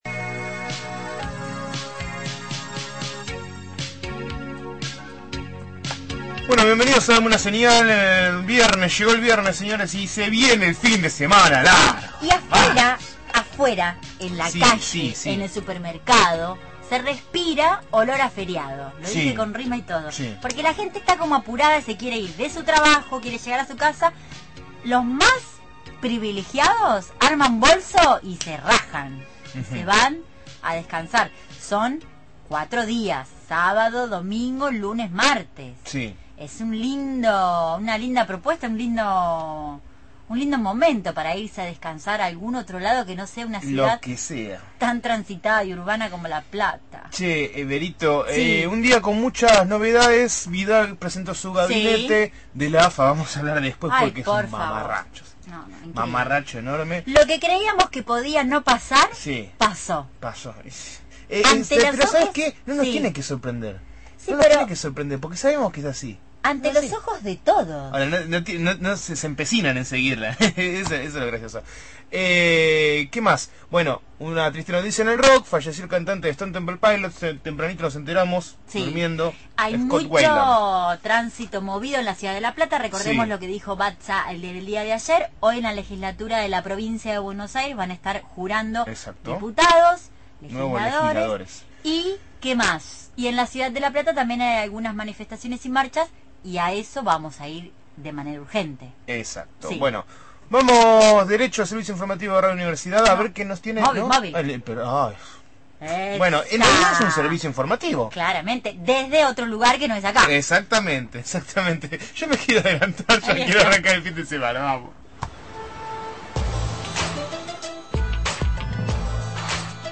MÓVIL/ La FULP y el ingreso a medicina – Radio Universidad
desde la presidencia de la UNLP, donde la FULP se manifestó sobre un proyecto de ingreso de estudiantes a la Facultad de Ciencias Médicas.